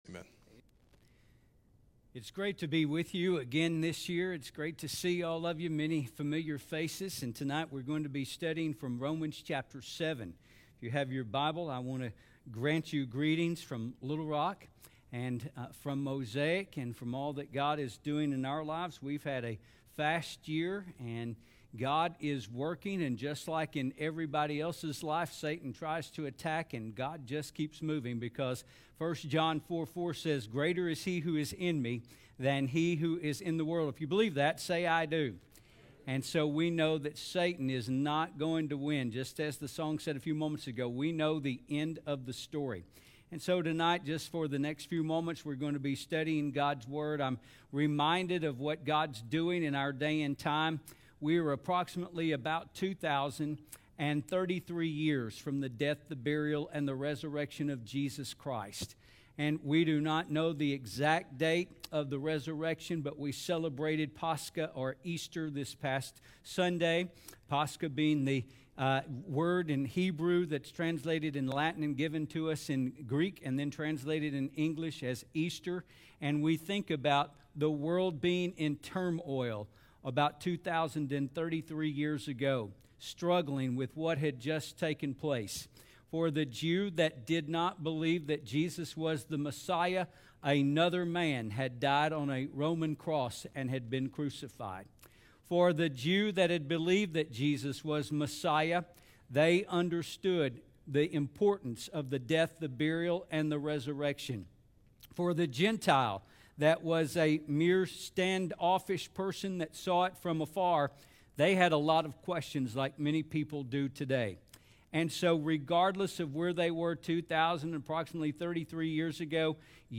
Sermons | Mount Vernon Baptist Church